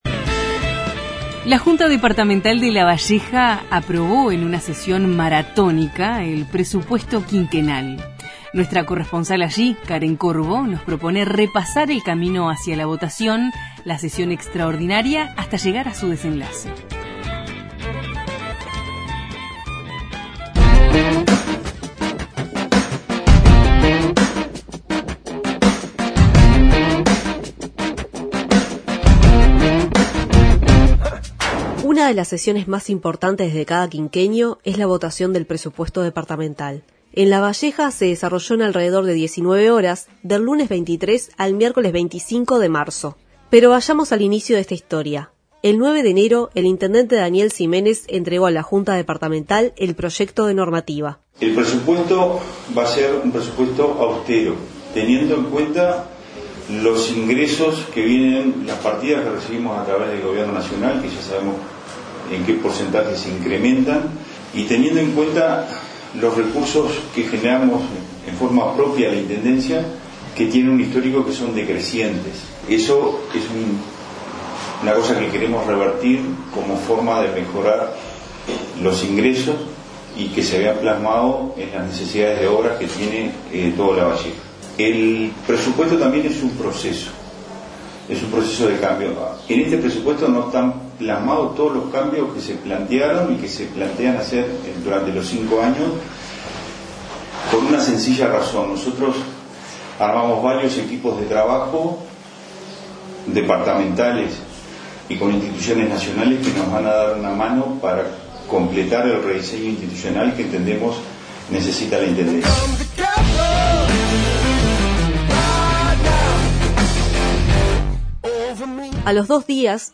Los informes de nuestros corresponsales en Lavalleja, Canelones y Tacuarembó.